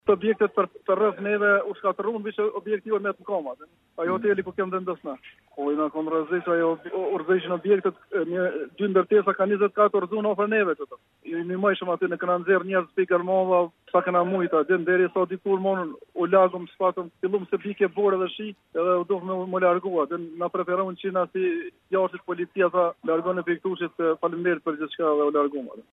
Deklarata